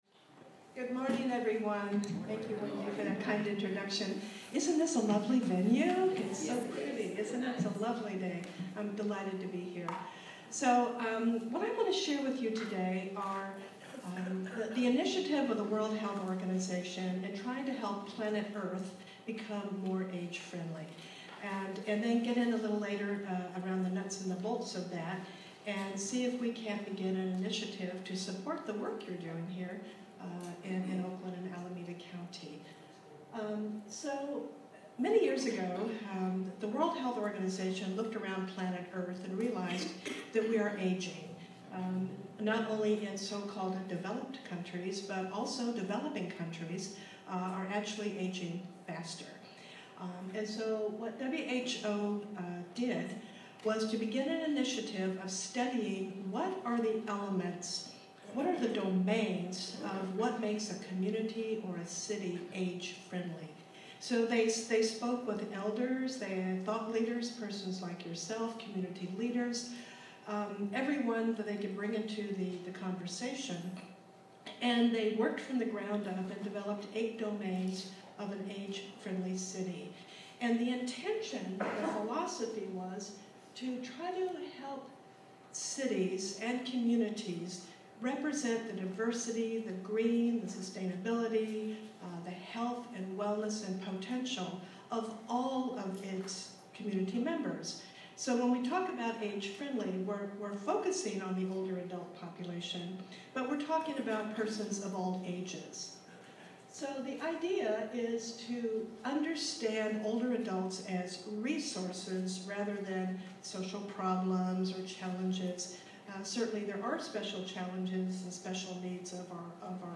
On Thursday, November 12, 2015 the Senior Services Coalition held a conference called Making the Difference: Creating Age-Friendly Institutions and Community in Alameda County.